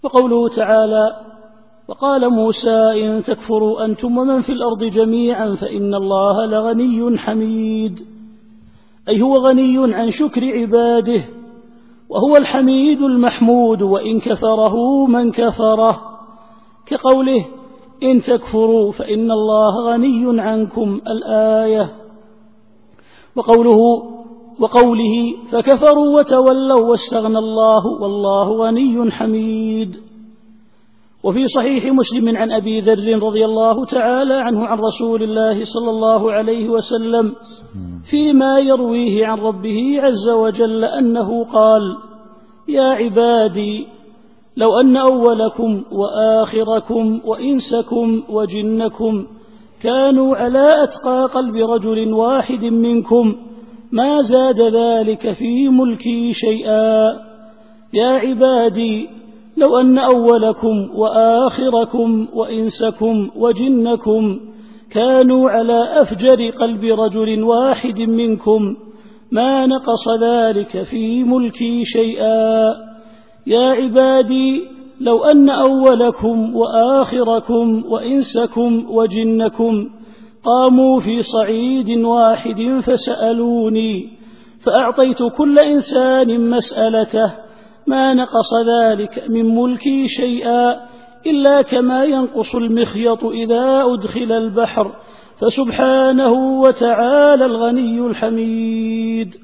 التفسير الصوتي [إبراهيم / 8]